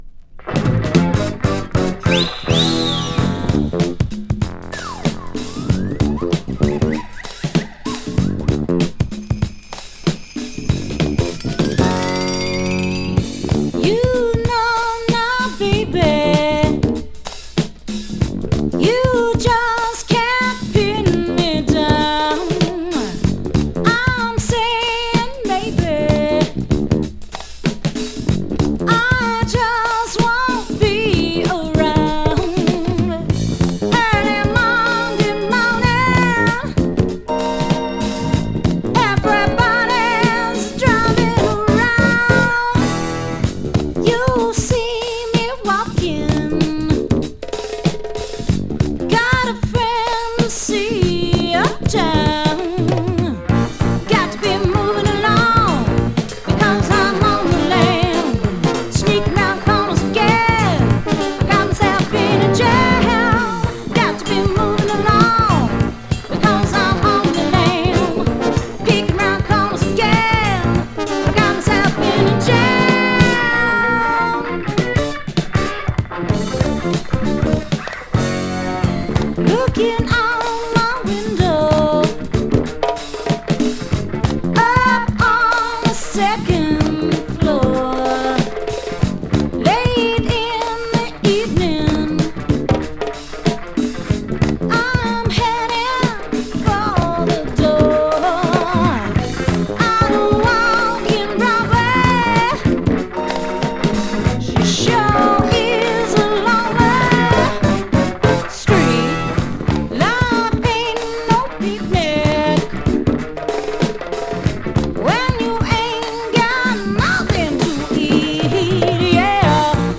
【FUSION】 【BOOGIE】
コロラド産オブスキュア・ジャズファンク！
女性シンガーをフューチャーしたファンキー・ソウル